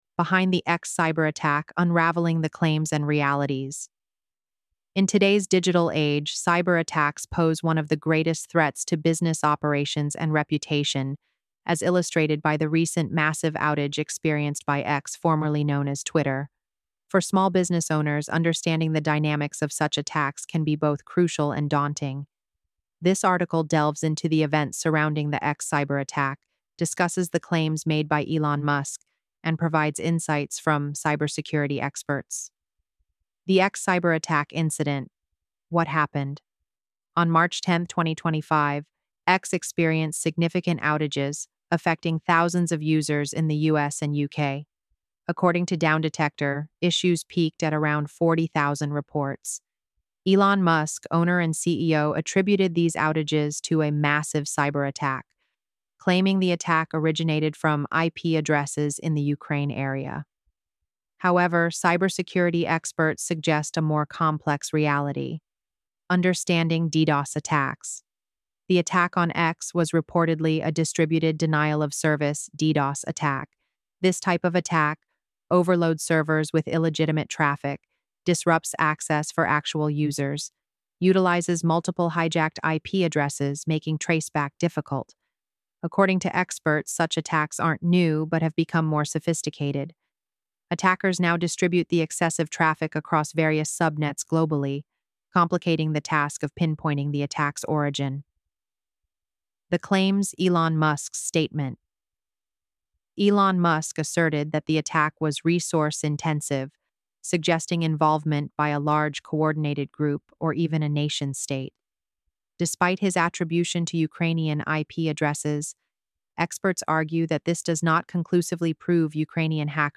Experts discuss the X cyberattack, exploring causes, impact, and cybersecurity measures.